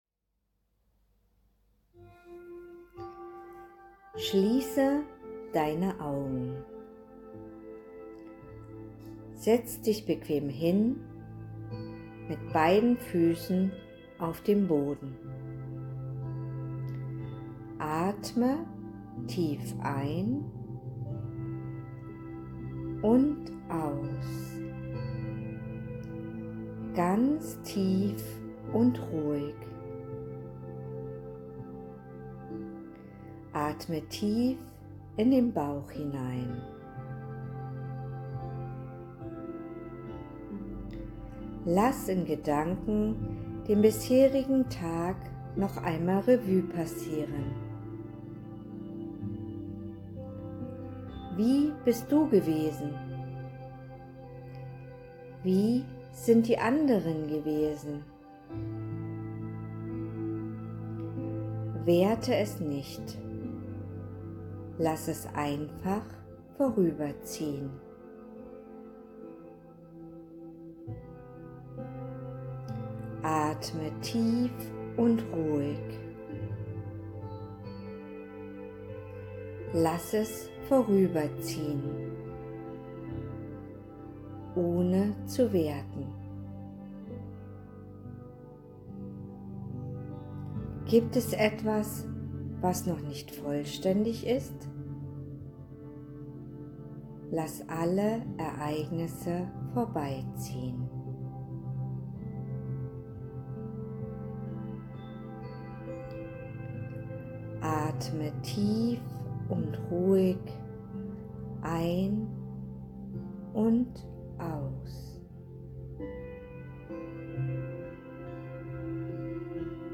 Gedankenreise zum Kennenlernen https